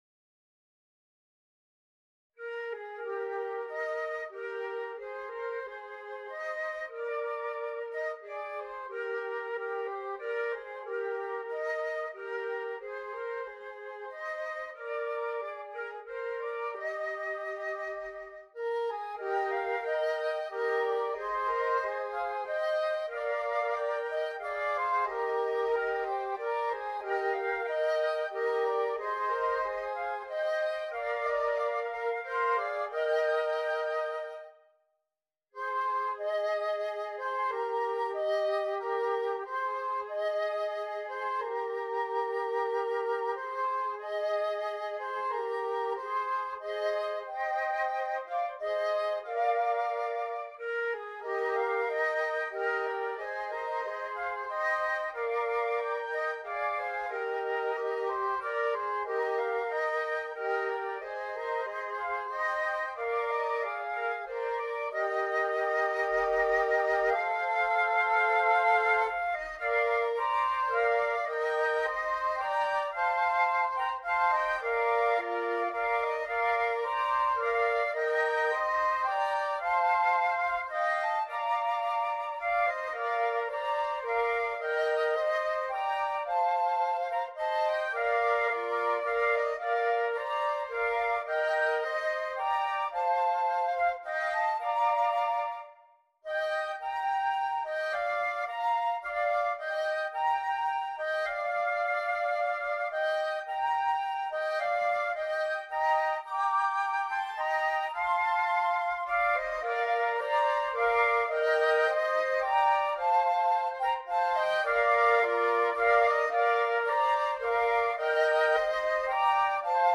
• 3 Flutes